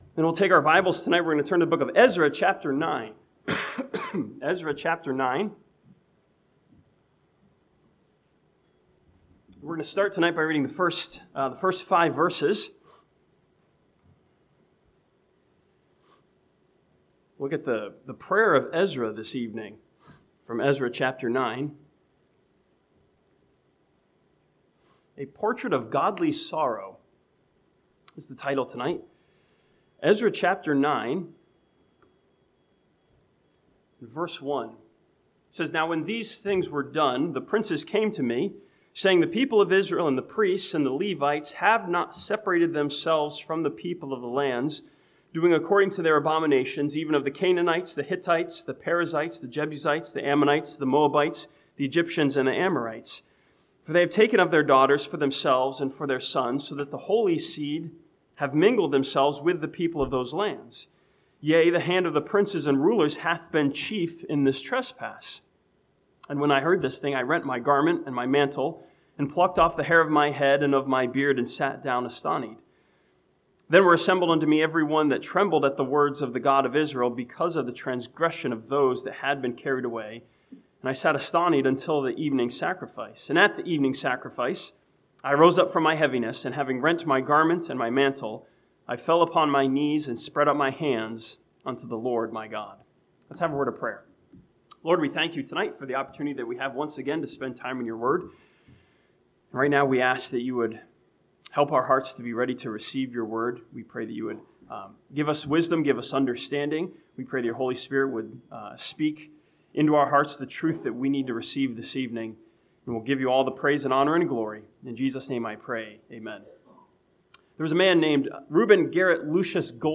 This sermon from Ezra chapter 9 challenges us to have godly sorrow over our sin as demonstrated by Ezra's prayer.